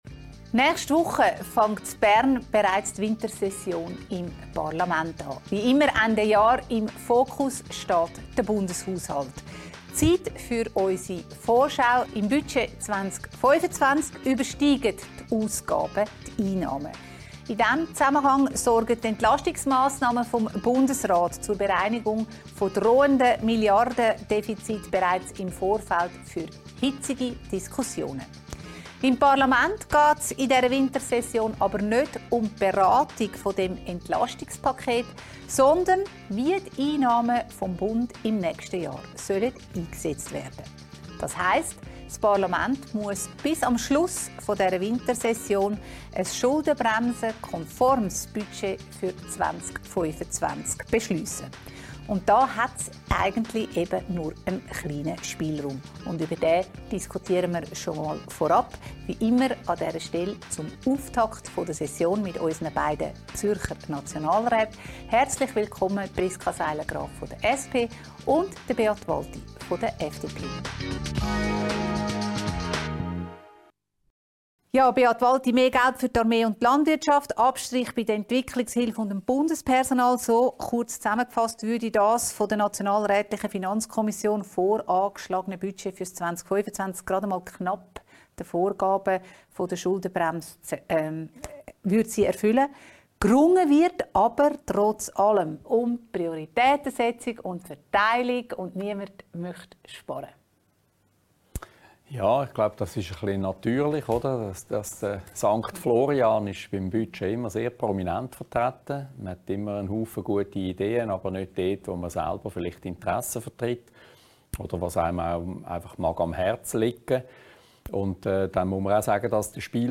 diskutiert mit den beiden Zürcher Nationalräten Priska Seiler Graf, SP und Beat Walti, FDP über die Finanzplanung und den Voranschlag 2025.